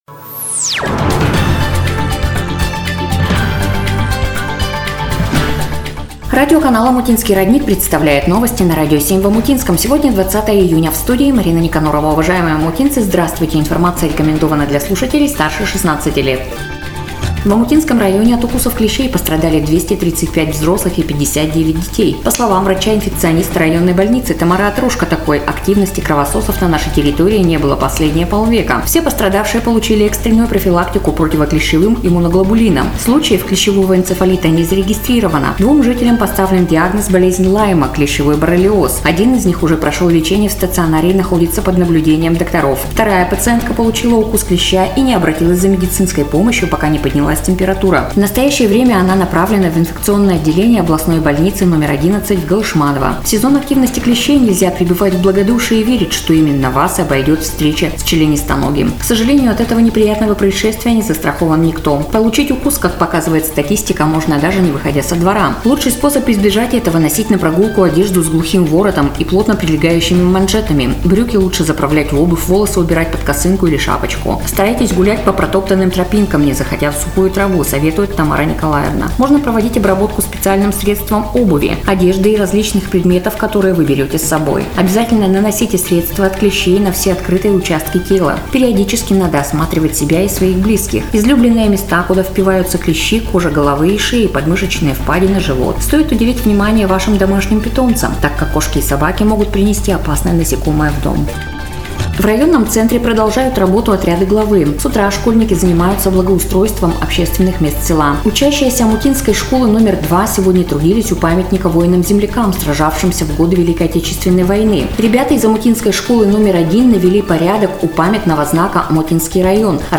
Эфир радиоканала "Омутинский родник" от 20 Июня 2025 года